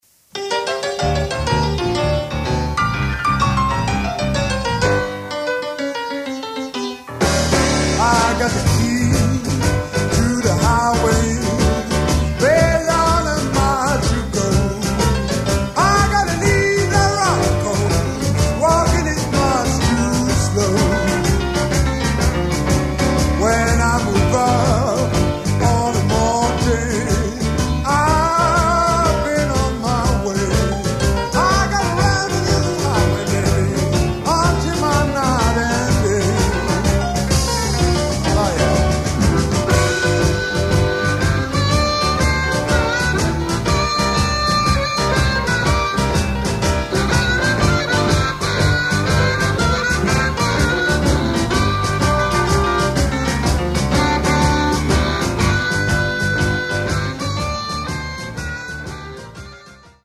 STUDIO, 12-13 september-79:
Sång, munspel
Gitarr 1-7 + 13
Piano
Trummor